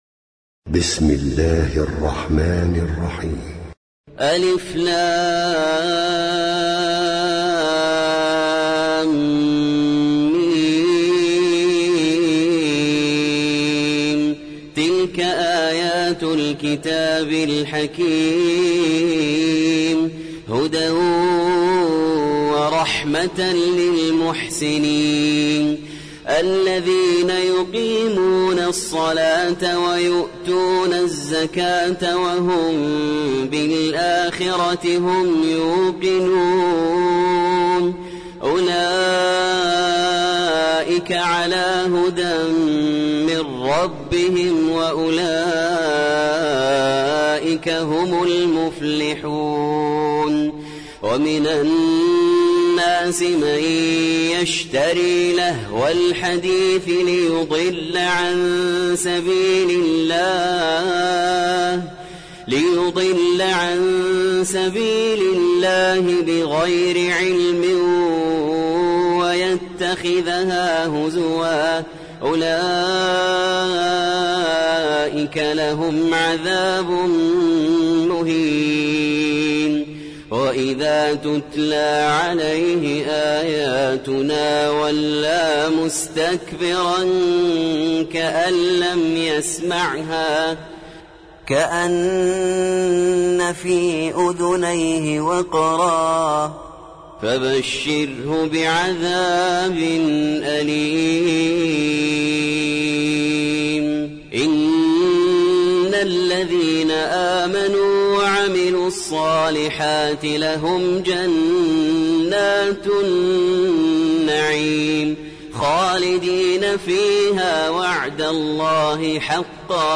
سورة لقمان - المصحف المرتل (برواية حفص عن عاصم)
جودة عالية